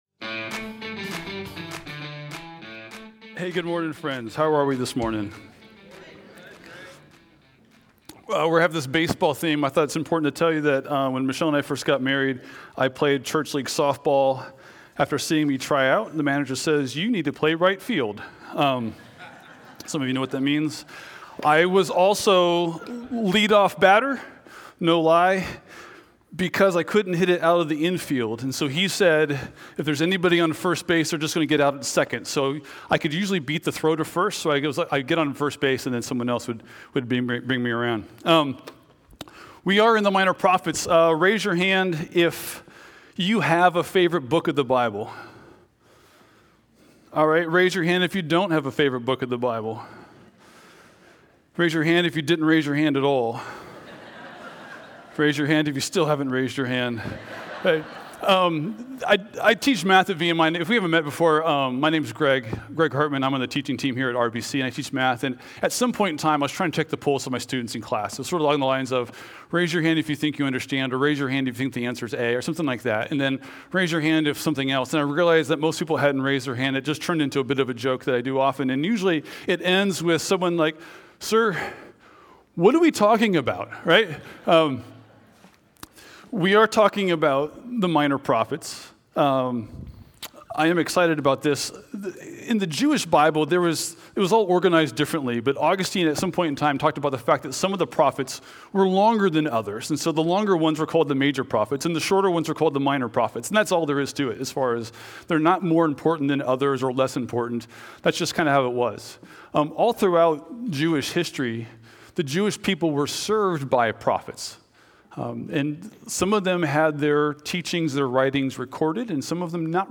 6-22-25 SermonOnly.mp3